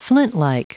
Prononciation du mot flintlike en anglais (fichier audio)
Prononciation du mot : flintlike